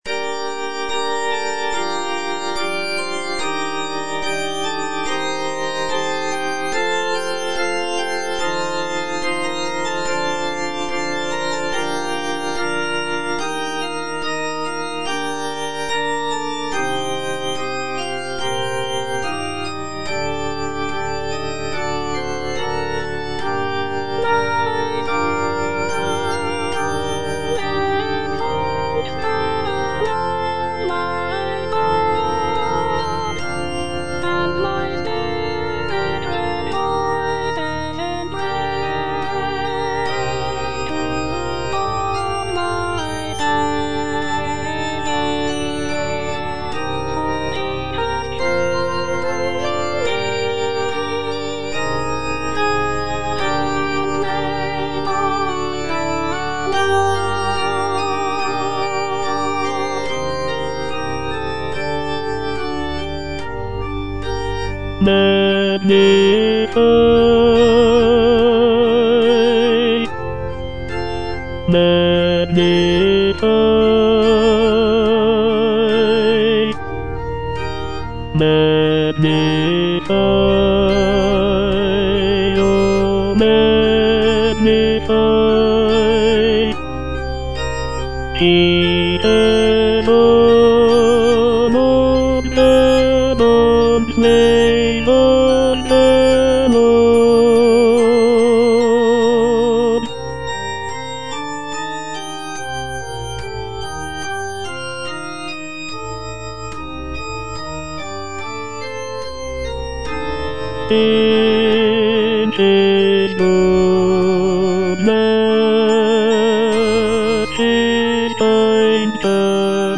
Tenor (Voice with metronome)
choral piece